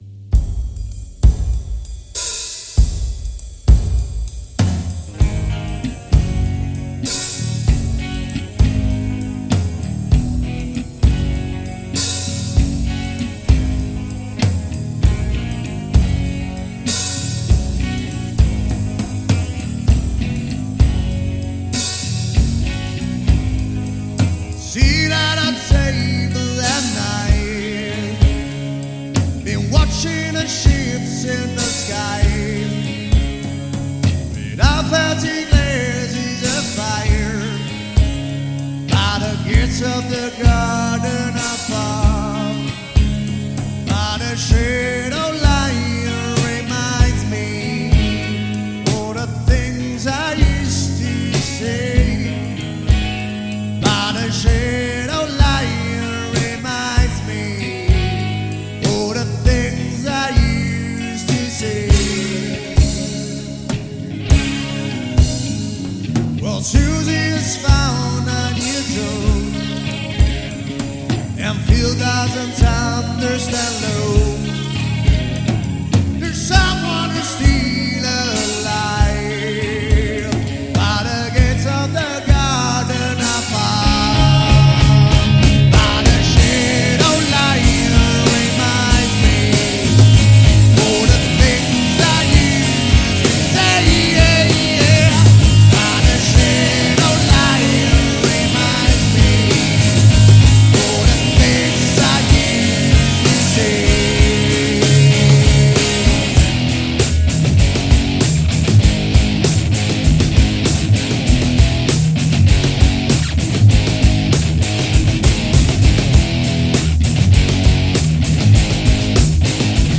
hard /southern rock band
vocals guitar
bass vocals
drums